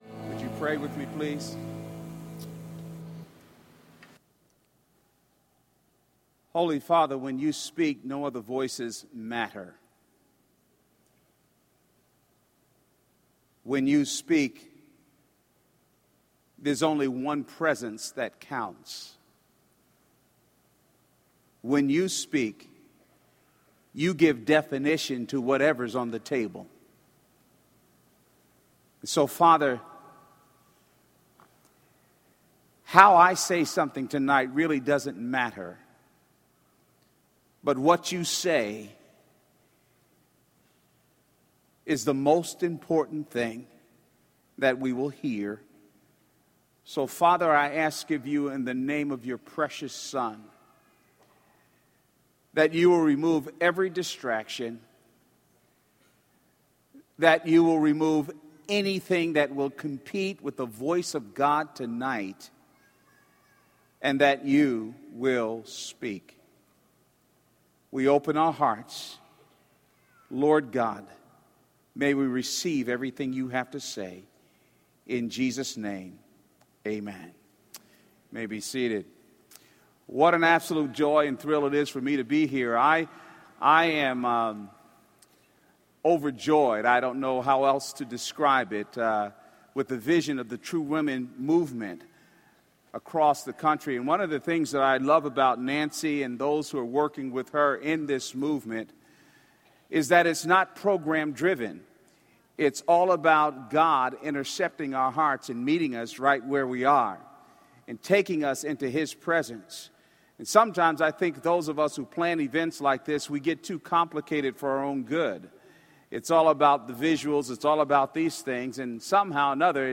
| True Woman '10 Fort Worth | Events | Revive Our Hearts